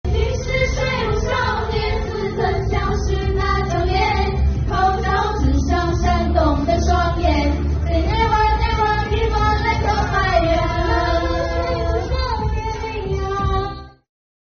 唱给你听